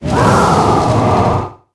Cri de Scolocendre dans Pokémon HOME.